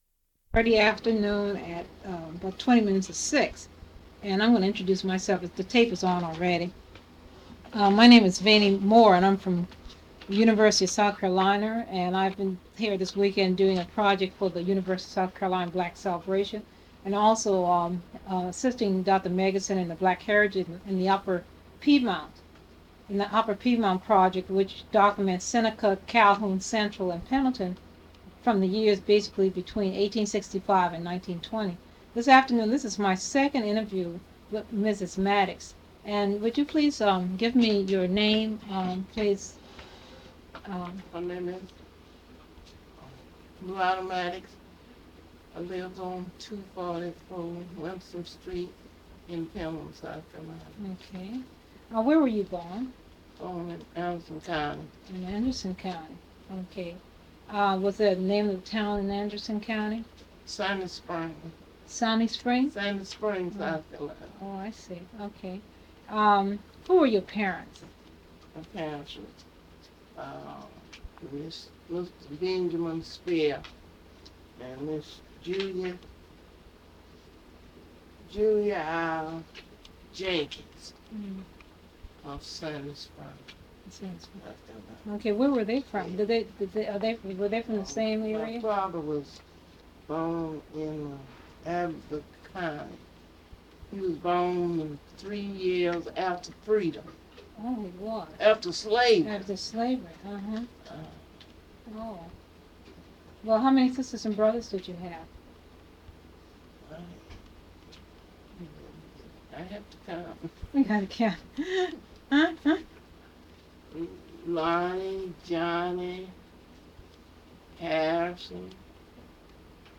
Part of Interview